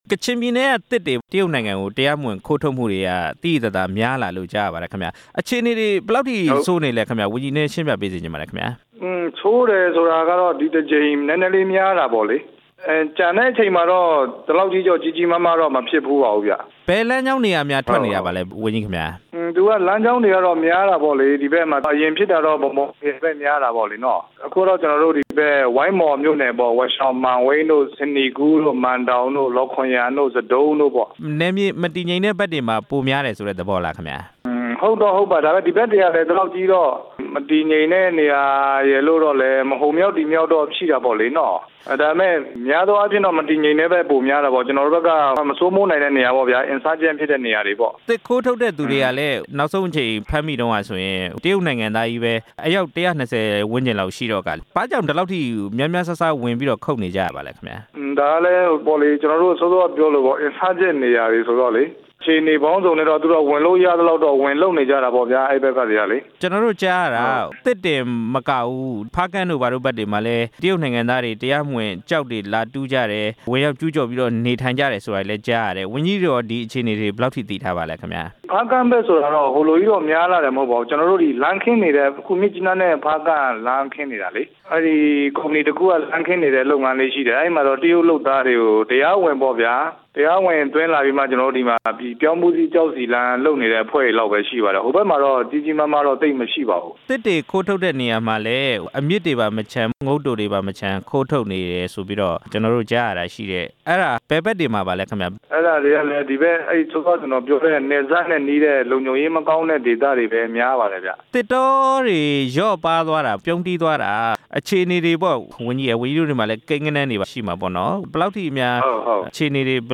ကချင်ပြည်နယ် သစ်ခိုးထုတ်မှု သစ်တောဝန်ကြီးနဲ့ မေးမြန်းချက်